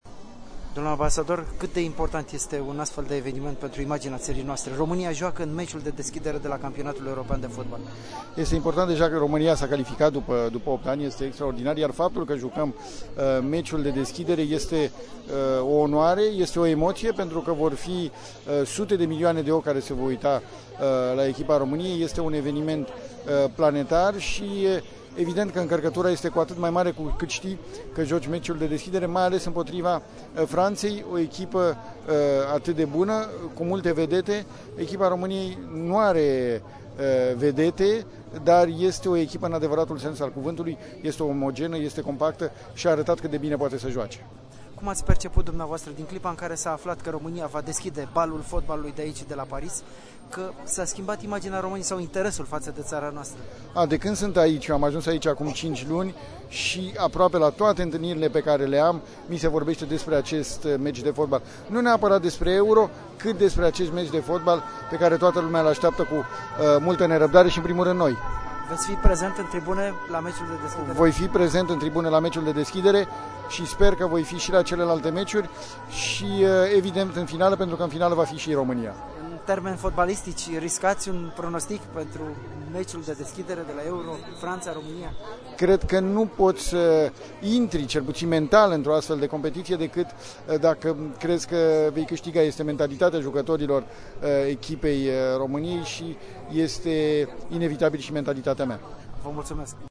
EXCLUSIV: Interviu cu ambasadorul României la Paris (audio)